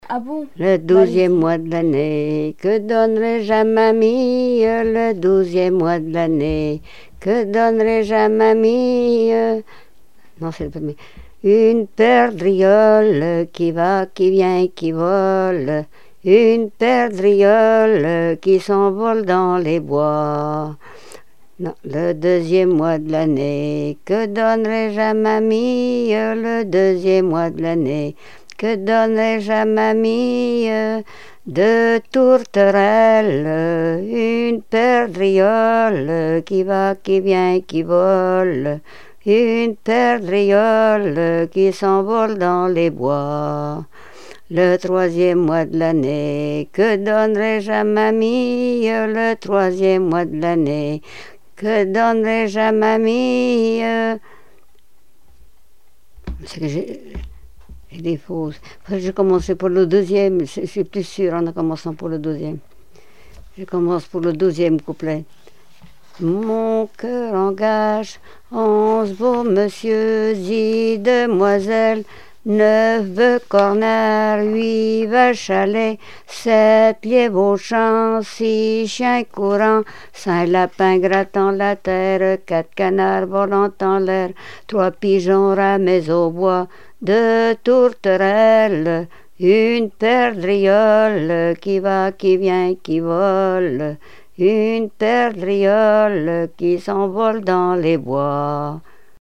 Répertoire de chansons traditionnelles et populaires
Pièce musicale inédite